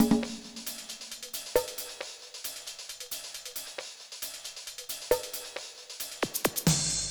KIN Beat - Full Perc 2.wav